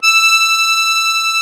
MUSETTE 1.19.wav